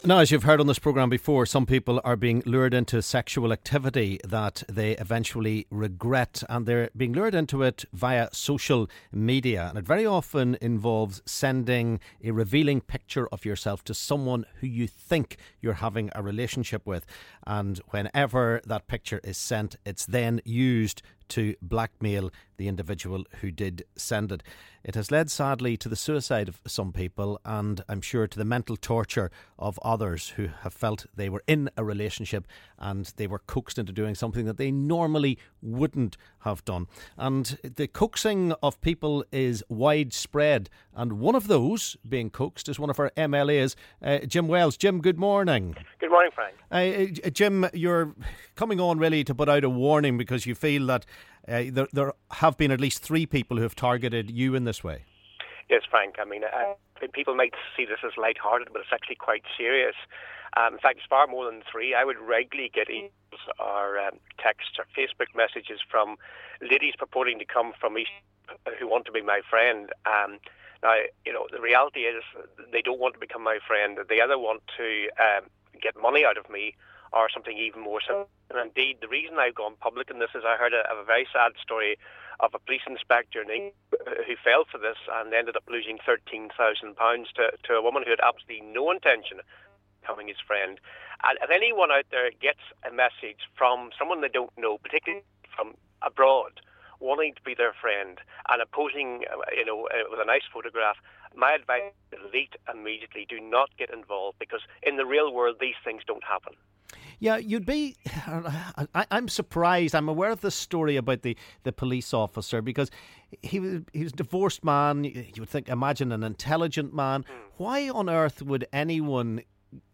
LISTEN: MLA Jim Wells & callers say they have been the target of blackmail attempts on Social Media